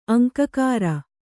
♪ aŋkakāra